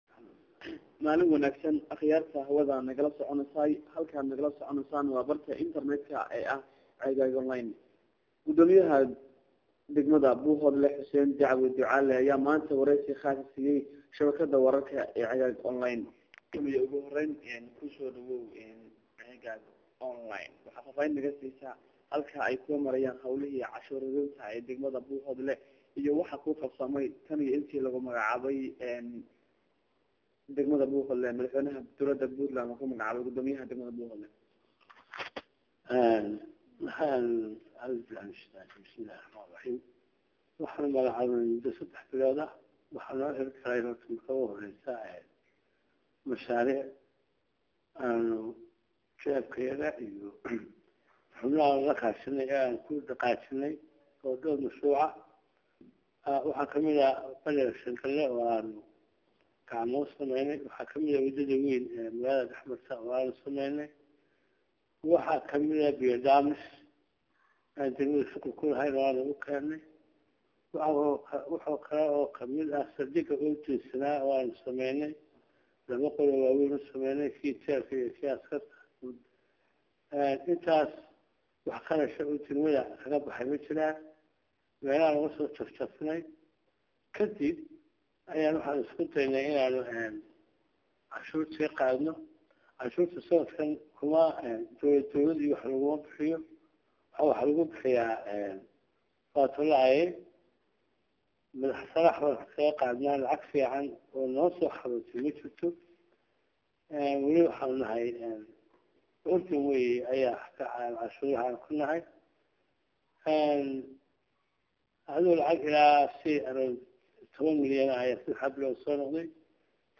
Gudoomiyaha Degmada Buuhoodle oow areysi khaas ah siisay Shabakada Ceegaag Online.
Waxaa Maanta Waraysi khaas ah Siiyey Shabakada Wararka ee Ceegaag Online gudoomiyaha degmada Buuhoodle Xuseen Dacwi Ducaale oo Ceegaag Onilne ku booqatay Xafiiskiisa xili uu ku jiray shaqada xafiiskiisa waxaana Maayarka magaalada buuhoodle Ceegaag online uga waramay waxyaabaha u Qabsoomay intii Xilka loo magacaabay iyo waliba waxqabadkiisa sanadkii hore.